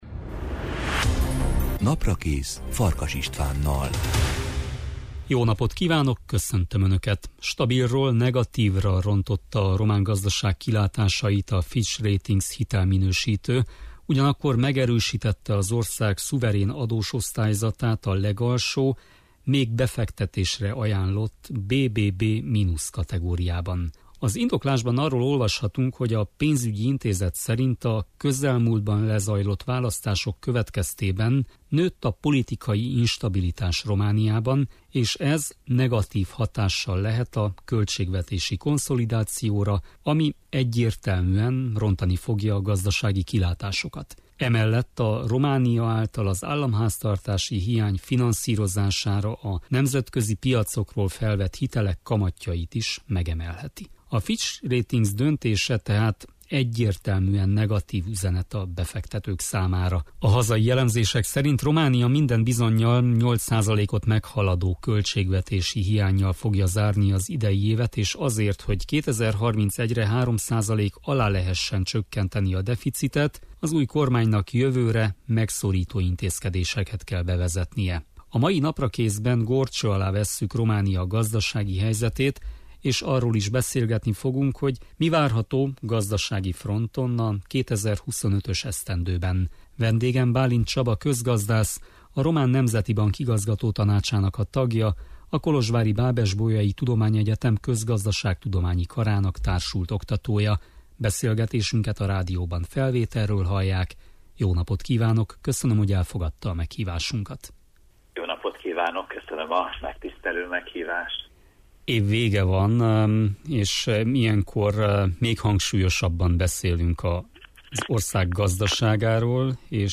A mai Naprakészben górcső alá vesszük Románia gazdasági helyzetét és arról is beszélgetni fogunk, hogy mi várható gazdasági fronton a 2025-ös évben.